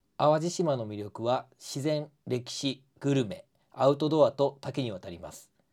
今回は、下記のようなナレーションを40分ほど収録しました。
▼元の声として準備した音声ファイル